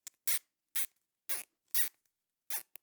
Zip Ties Secure Sound
household